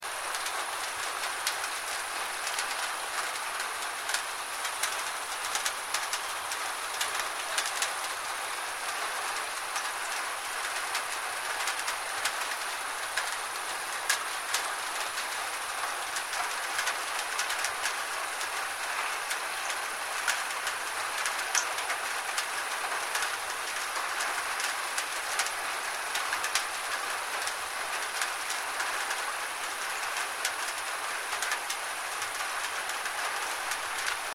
The-sound-of-rain-on-a-tin-roof.mp3